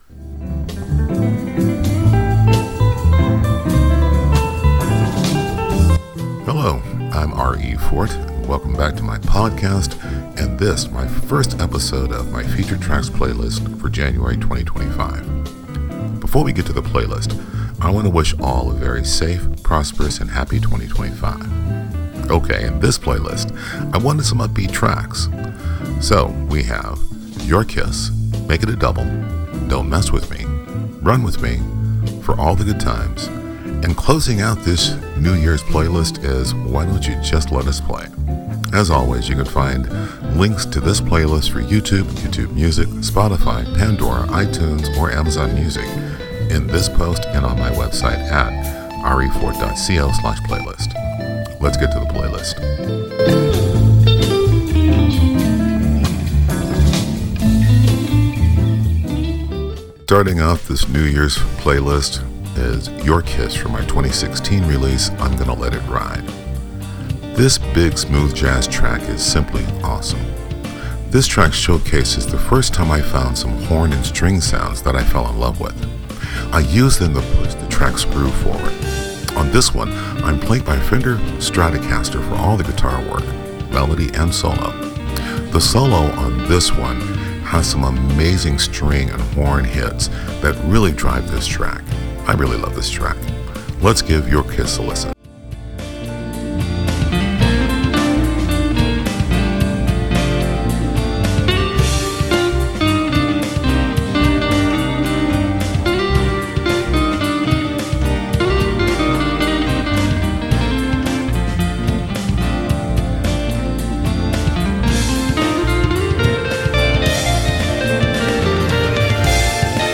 I wanted some upbeat tracks.
This big smooth jazz track is simply awesome.